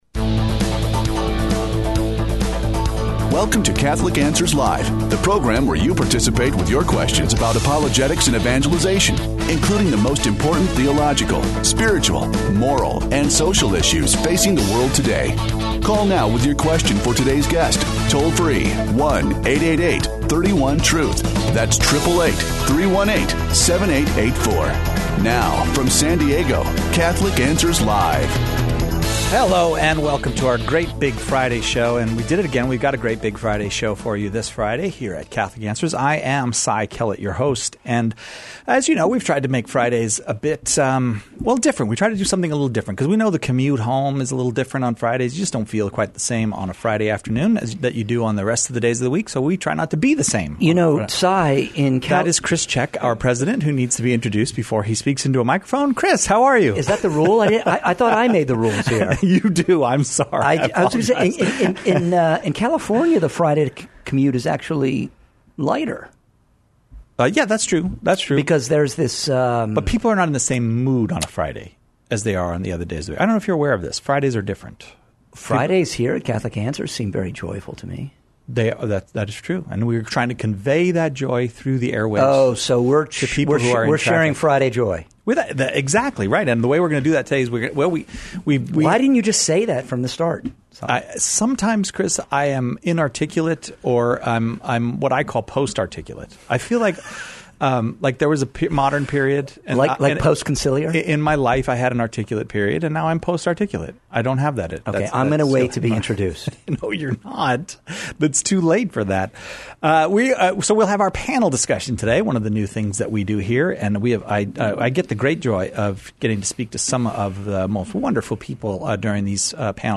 Panel Show